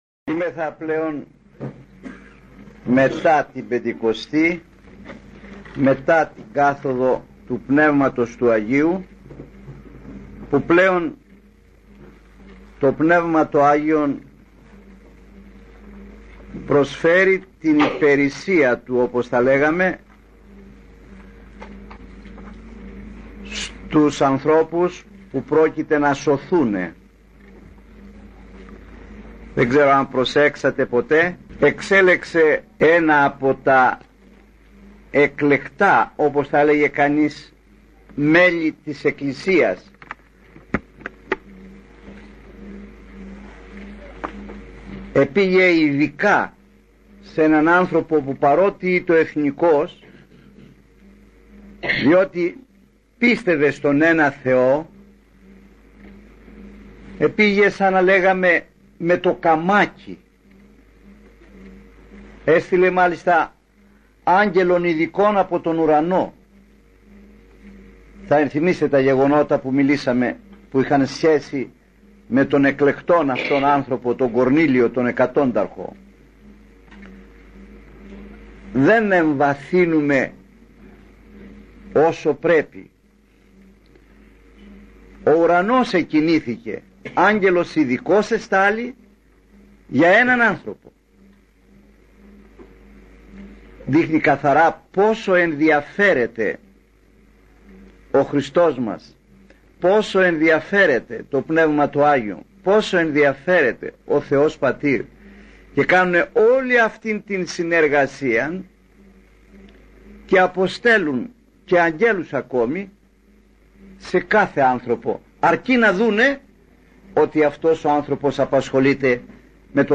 ηχογραφημένη ομιλία